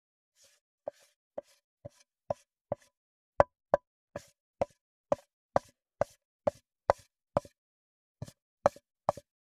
580魚切る,肉切りナイフ,
効果音厨房/台所/レストラン/kitchen食器食材
効果音